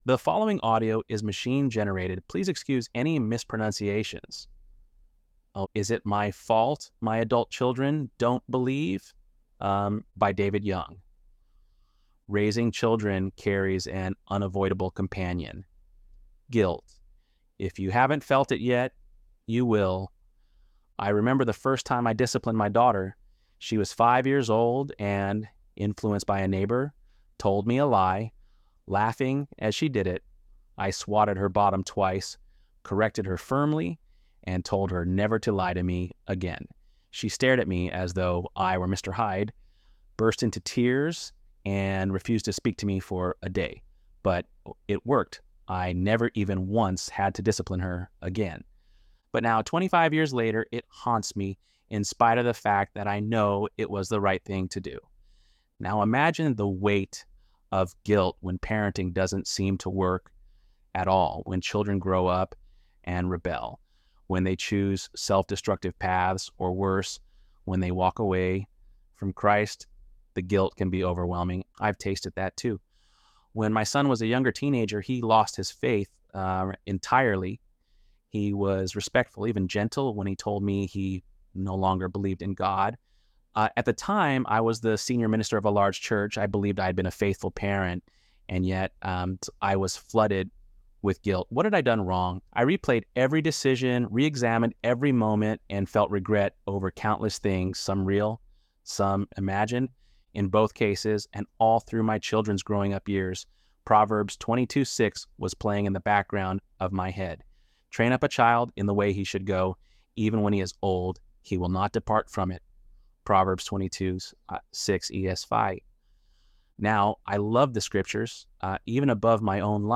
ElevenLabs_Untitled_project-45.mp3